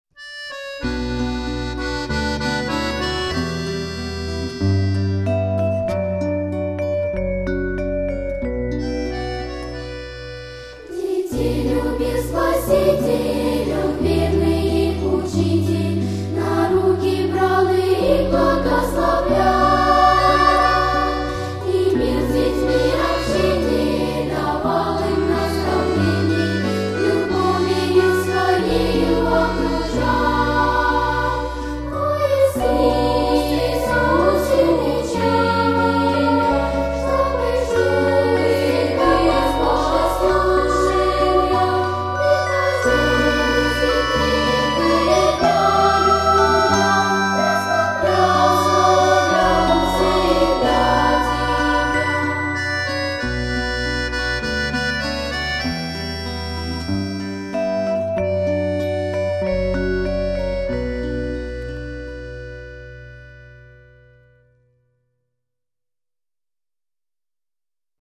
01 Детей любил Спаситель (пение).mp3